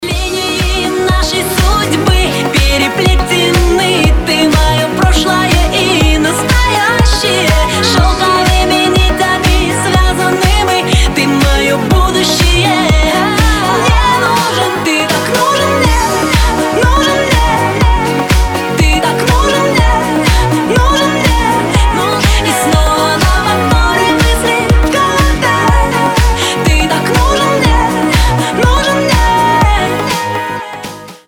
поп
женский вокал